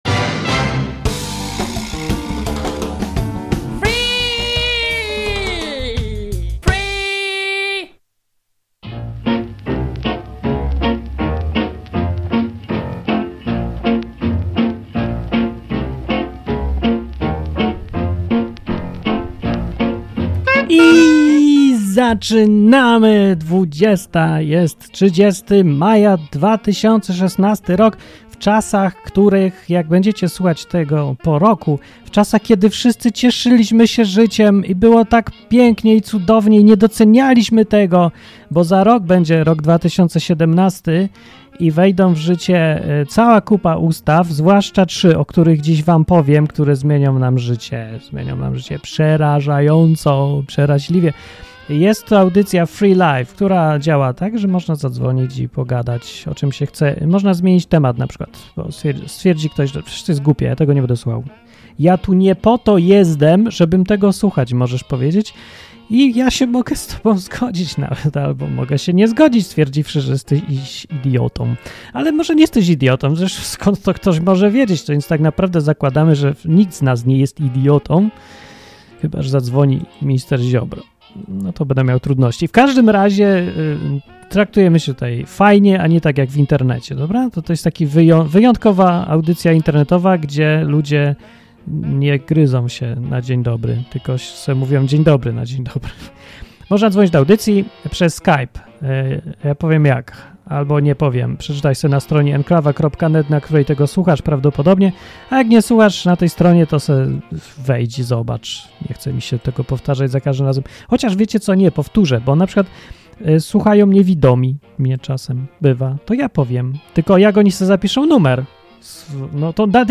Free! na żywo! Program dla wszystkich, którzy lubią luźne, dzikie, improwizowane audycje na żywo.